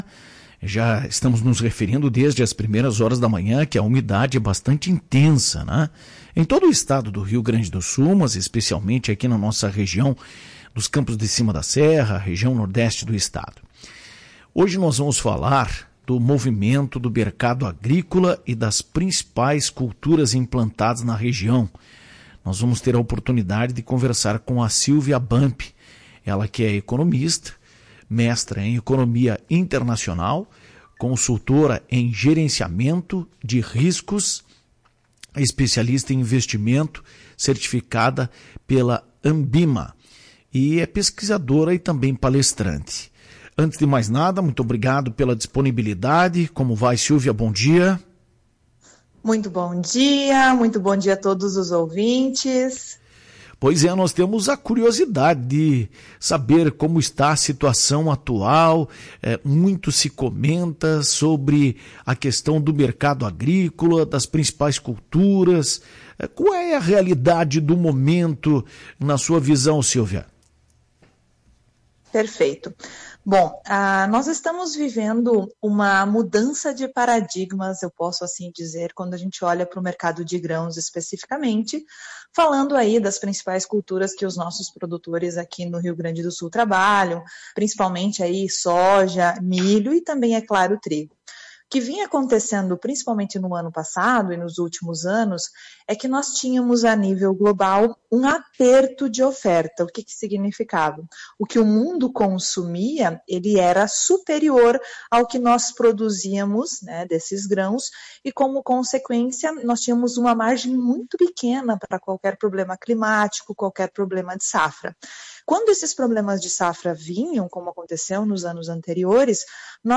A entrevistada é economista, mestra em economia Internacional.